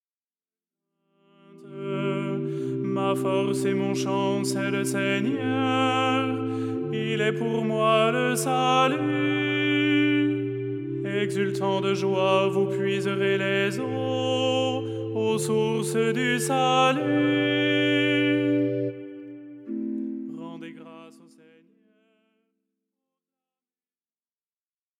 style simple et chantant